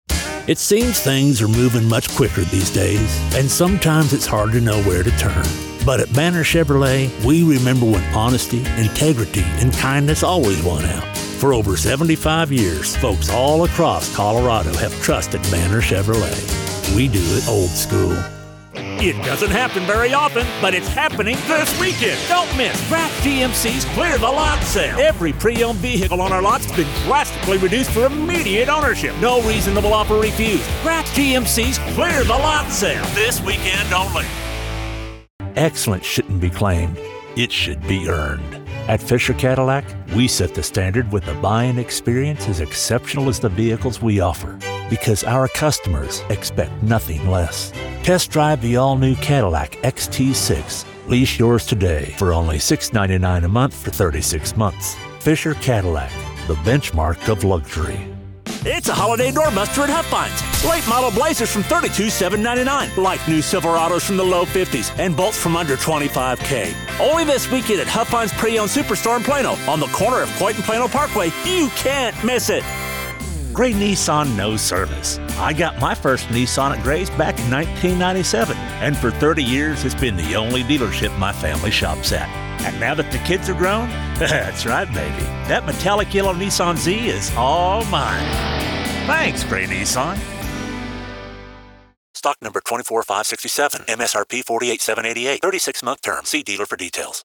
Automotive Demo
English - Southern U.S. English
Western, Cowboy, Aviation, Pilot, Rugged, Authoritative, Trustworthy, Storytelling, Corporate, eLearning, Training, American, Mature, Deep, Confident, Experienced, Captain, Rodeo, Outdoor, Agriculture, Safety, Technical, Instructional, Reliable, Professional, Broadcast, Gritty, Grit, Gravel, Gravely, Commercials, Video games, Animation, Cartoon, Disney, Sam Elliott, Cinematic, Narration, Americana, Down-to-earth, Warm, Classic, Strong, Masculine, Character, Adventure, Frontier, Real, Western Villain, Drill Sargent, baritone, Upbeat, Happy, Conversational, teen, teenager, evil, manly, Rich, Resonant, Gravelly, Booming, Grizzled, Earthy, Smoky, Weathered, Matured, Full-bodied, Deep-toned, Bass, Low, Warmth, Textured, Frontiersman, Plainsman, Outlaw, wrangler, rancher